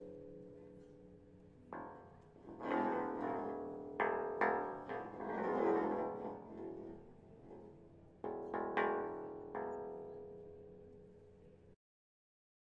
金属H型梁上的玻璃底板 - 声音 - 淘声网 - 免费音效素材资源|视频游戏配乐下载
这是通过在垂直的金属H杆上上下刮一个瓶子来完成的。用Zoom H6录音机和胶囊录制。